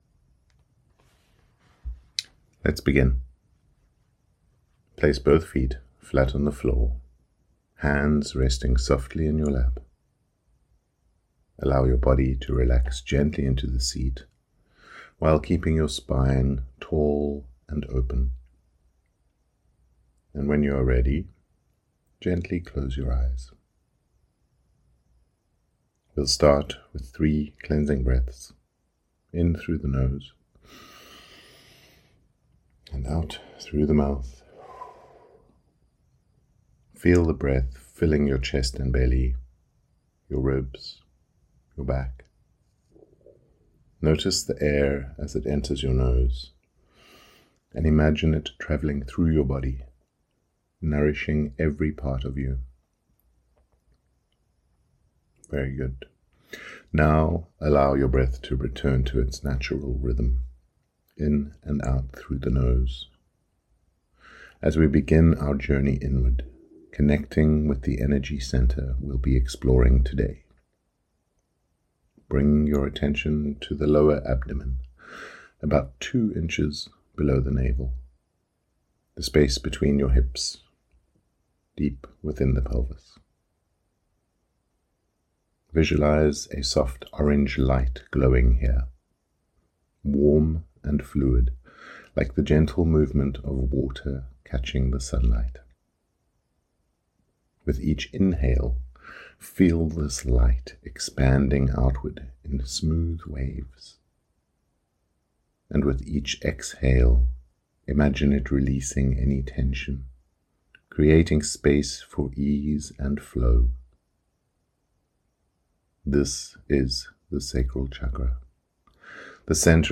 Sacral Chakra Meditation
CH02-meditation.mp3